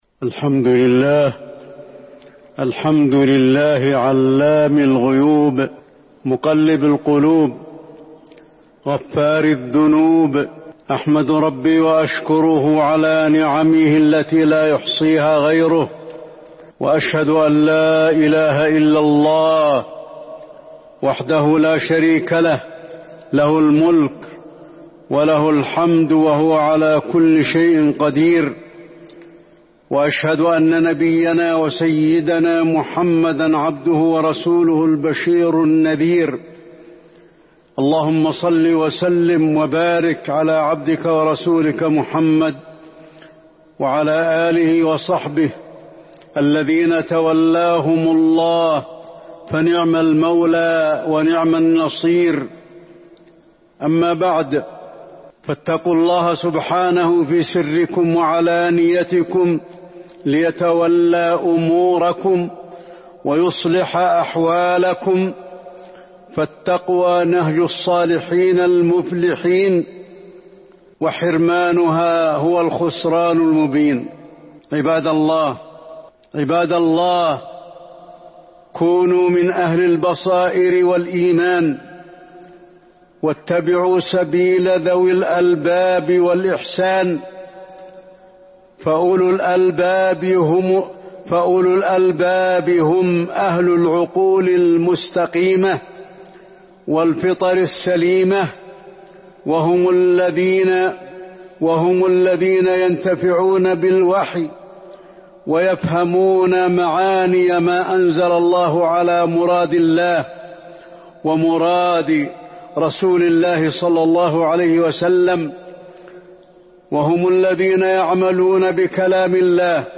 تاريخ النشر ٢ محرم ١٤٤٢ هـ المكان: المسجد النبوي الشيخ: فضيلة الشيخ د. علي بن عبدالرحمن الحذيفي فضيلة الشيخ د. علي بن عبدالرحمن الحذيفي الاعتبار والتفكر في خلق الله